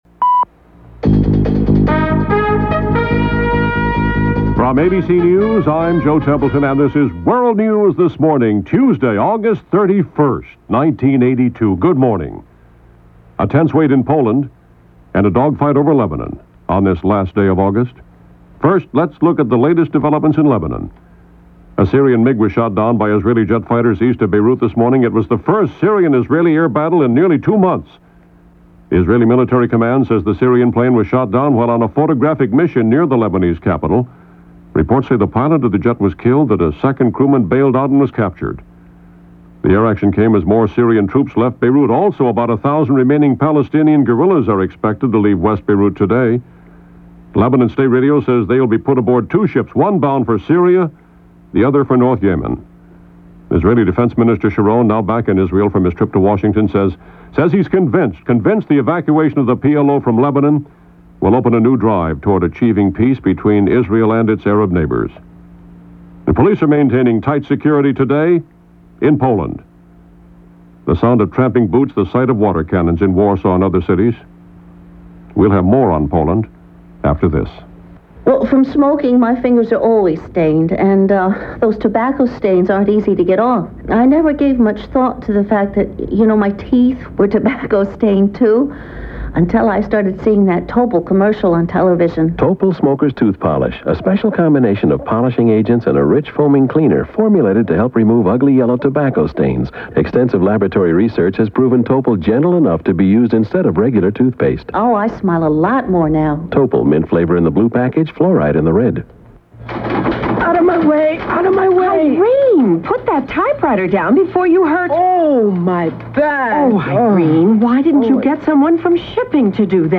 Meanwhile, the sound of Police and water cannons in the streets of Warsaw, as demonstrations in Poland were threatening again.
And that’s just a small slice of the goings-on for this day, as reported by ABC Radio’s World News This Morning.